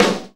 JAZZ SNR 3.wav